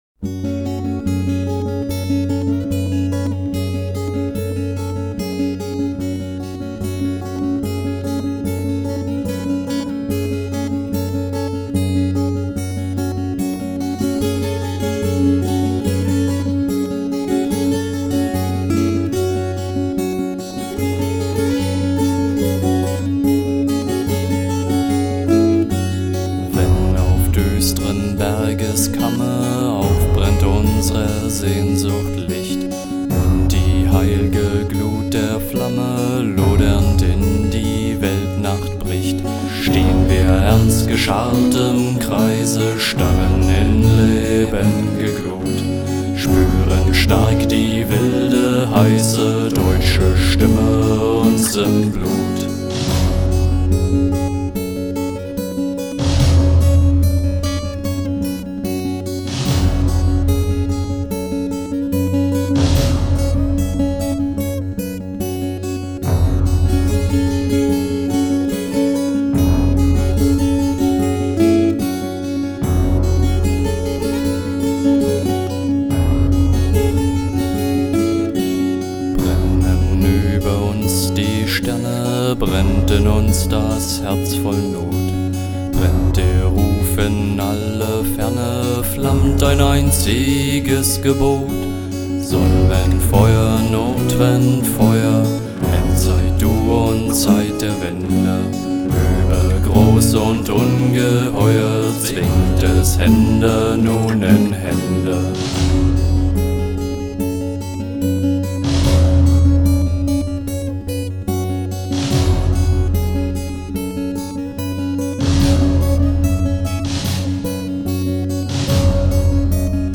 Стиль: Dark Folk